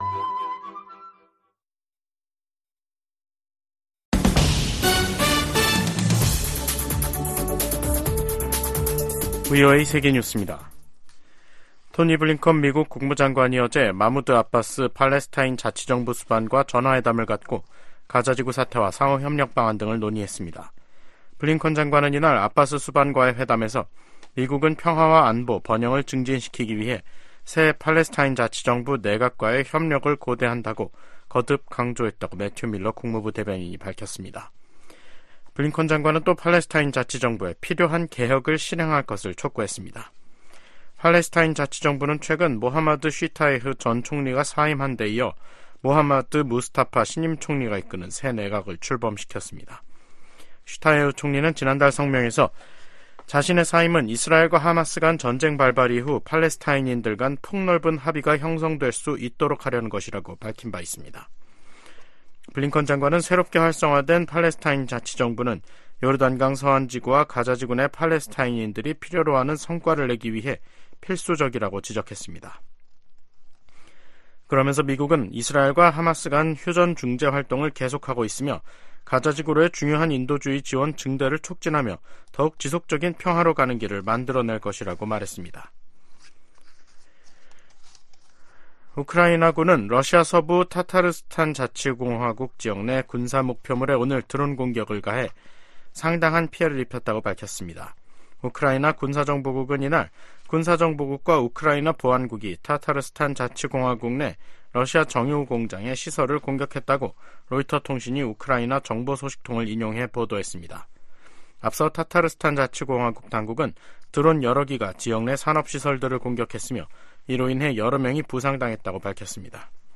VOA 한국어 간판 뉴스 프로그램 '뉴스 투데이', 2024년 4월 2일 2부 방송입니다. 북한이 보름 만에 또 다시 중거리 극초음속 미사일로 추정되는 탄도미사일을 동해상으로 발사했습니다. 미국은 러시아가 북한 무기를 받은 대가로 유엔 대북제재 전문가패널의 임기 연장을 거부했다고 비판했습니다. 주한미군이 중국과 타이완 간 전쟁에 참전할 경우 한국도 관여를 피하기 어려울 것으로 전 주일미군사령관이 전망했습니다.